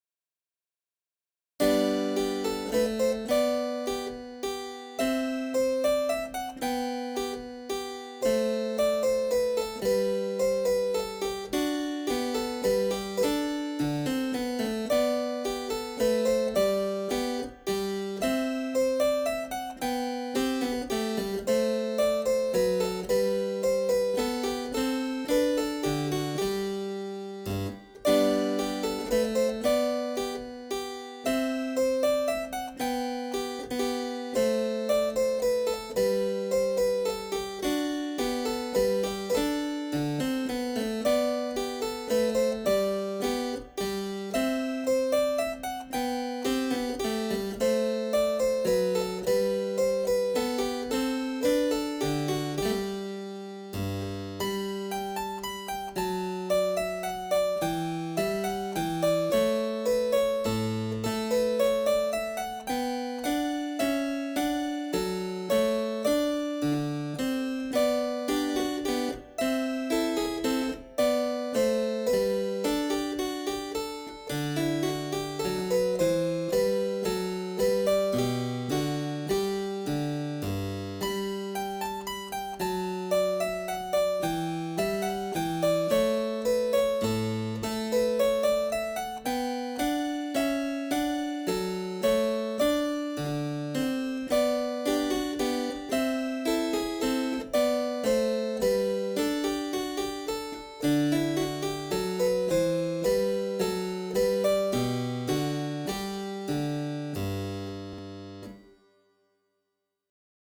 序盤よくわからないミスがありましたが、押し通しました ...
聴いてると転びそうになる演奏